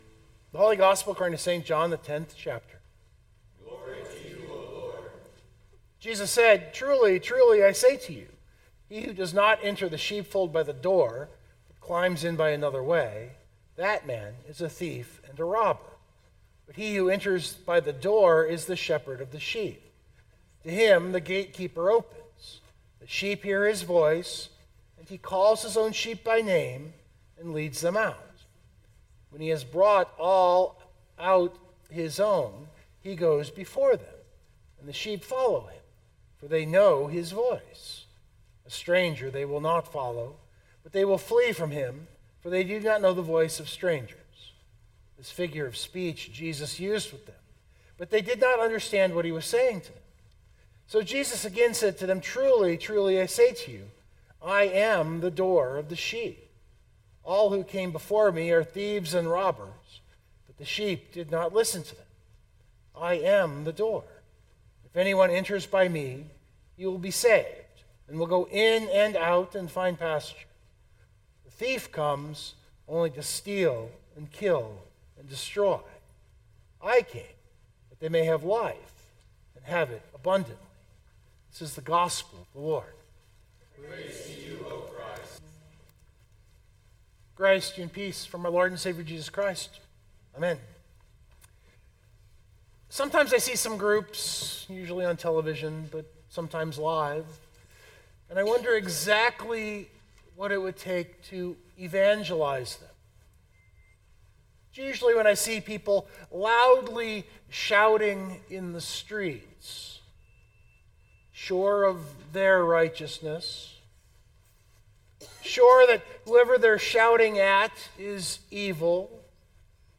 And that is what this sermon meditates on a bit.